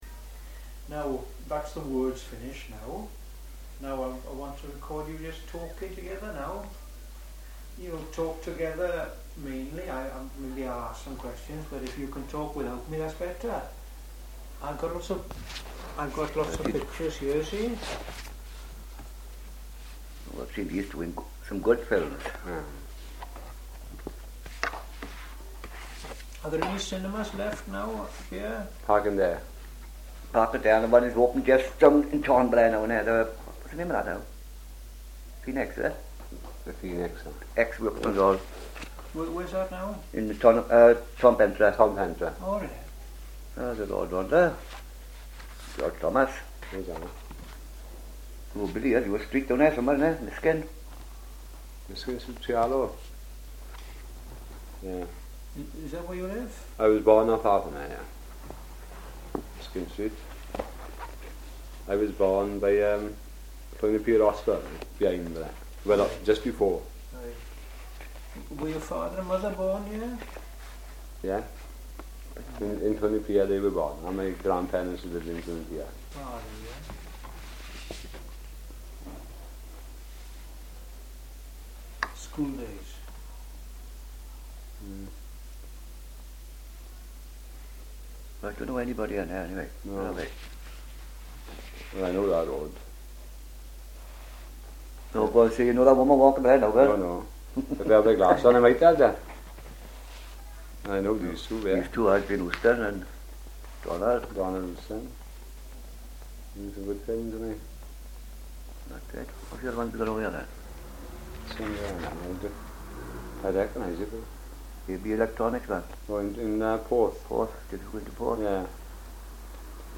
Treherbert9Conversation.mp3